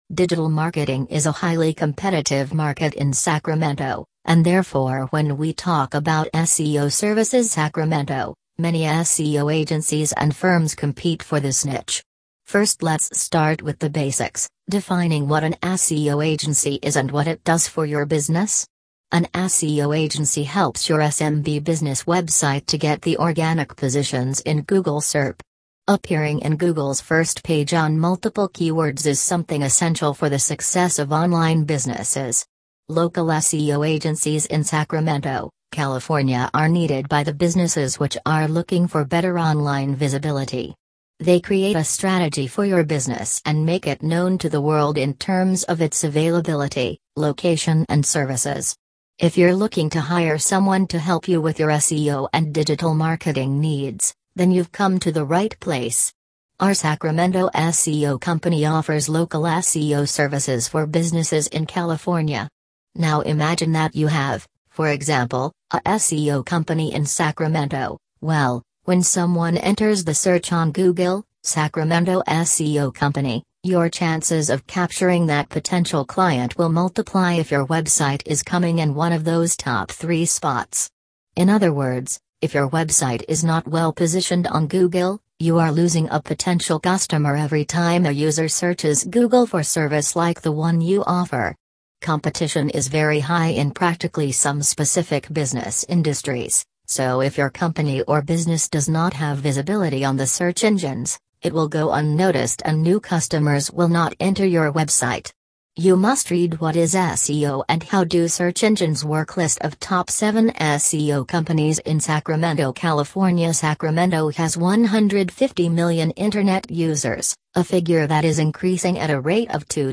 Do you Feel Lazy Reading A Blog Post? Here is its audio version